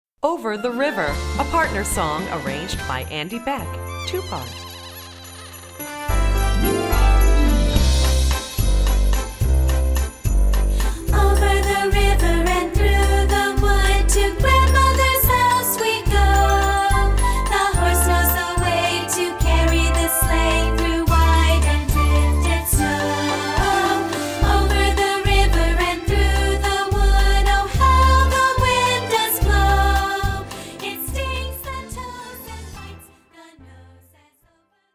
galloping hooves and horse whinnies